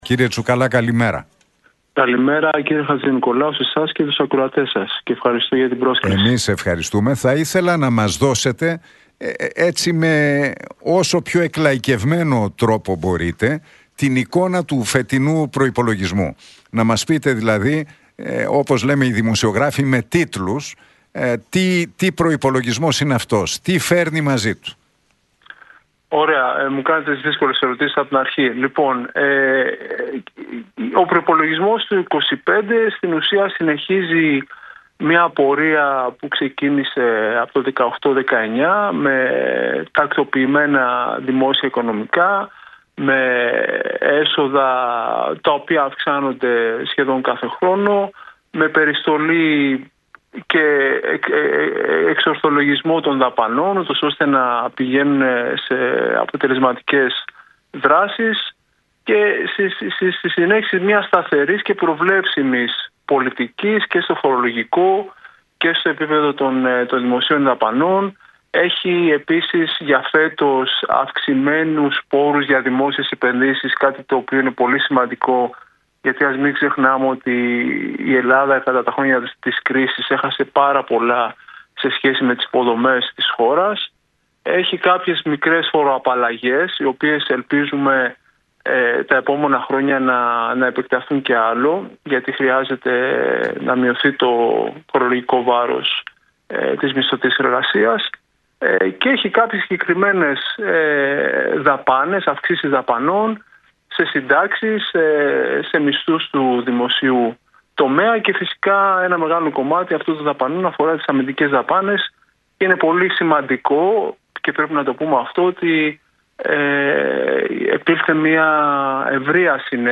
Την εικόνα του φετινού προϋπολογισμού ανέλυσε ο επικεφαλής του Γραφείου Προϋπολογισμού του Κράτους στη Βουλή, Γιάννης Τσουκαλάς στον Νίκο Χατζηνικολάου από την συχνότητα του Realfm 97,8.